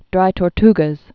(drī tôr-tgəz)